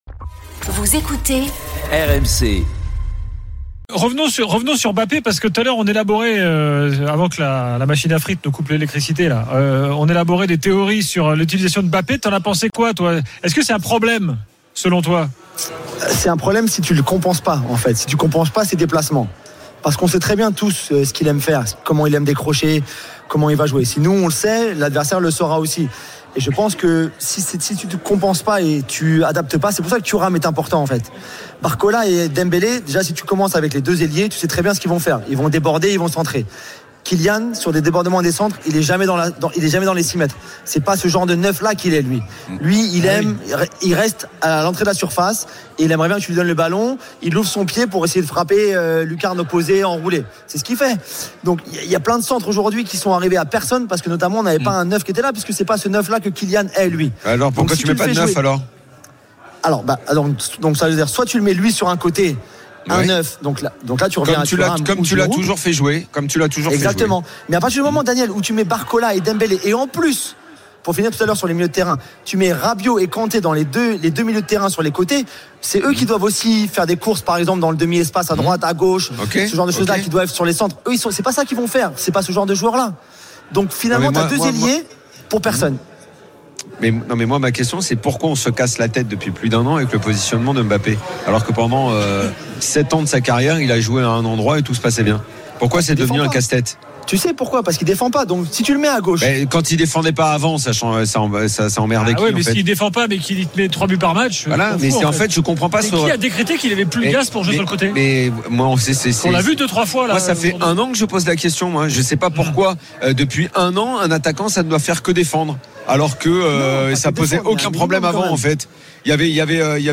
L’After foot, c’est LE show d’après-match et surtout la référence des fans de football depuis 15 ans !
RMC est une radio généraliste, essentiellement axée sur l'actualité et sur l'interactivité avec les auditeurs, dans un format 100% parlé, inédit en France.